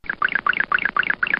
PLAY vv_run away cartoon 2
vv-run-away-cartoon.mp3